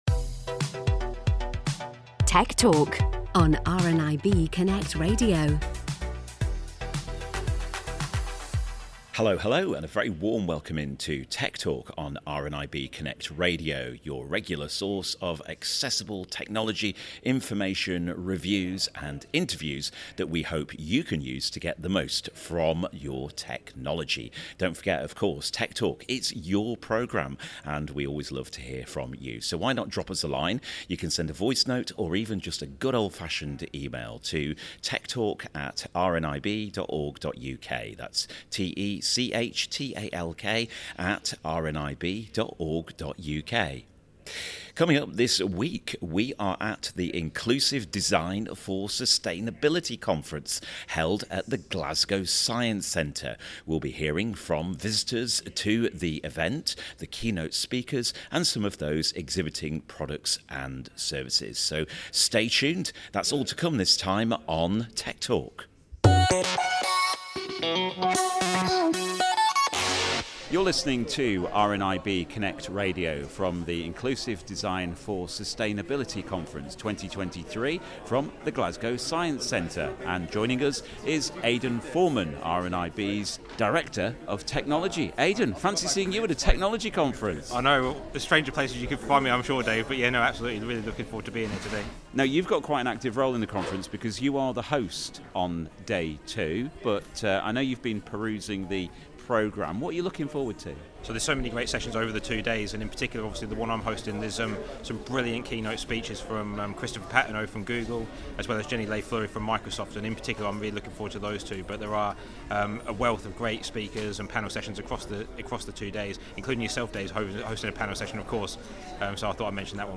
This week we're in Glasgow for RNIB's 'Inclusive Design For Sustainability' Conference. We hear from Sony, Google, WeWalk and more.